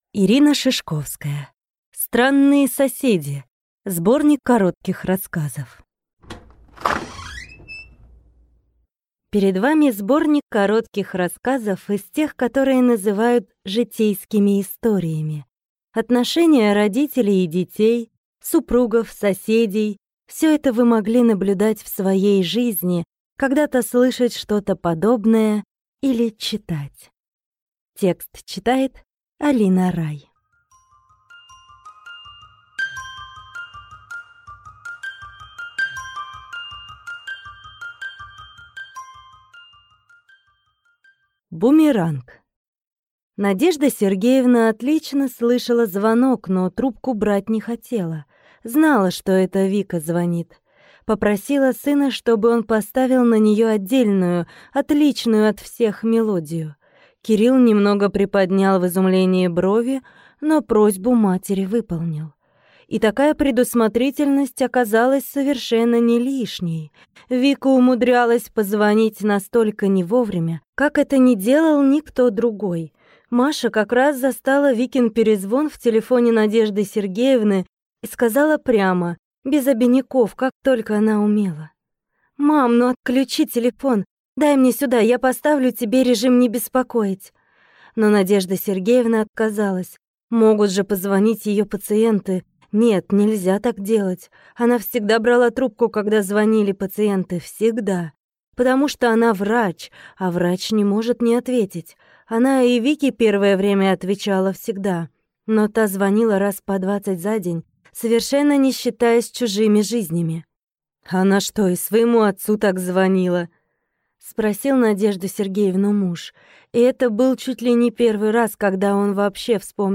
Аудиокнига Странные соседи | Библиотека аудиокниг